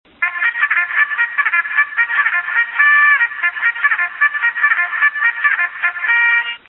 002_poplach.mp3